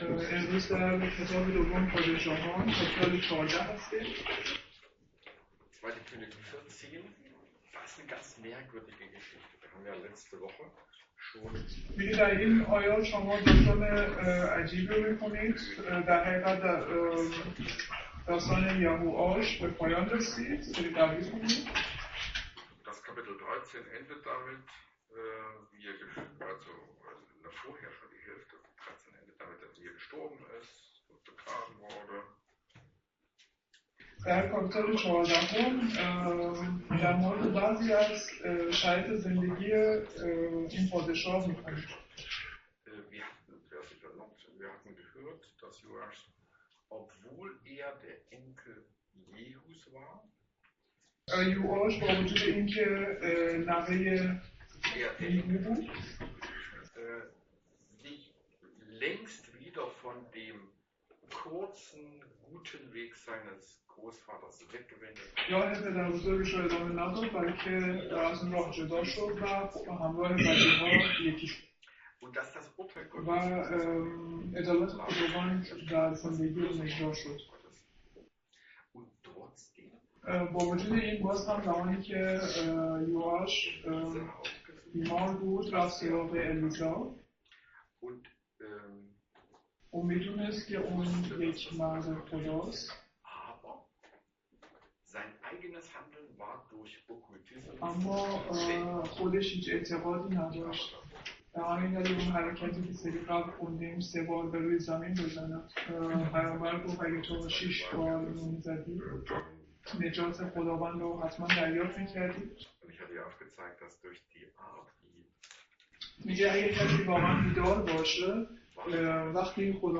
Könige 14,1 – 15 | Übersetzung in Farsi